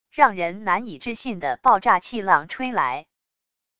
让人难以置信的爆炸气浪吹来wav音频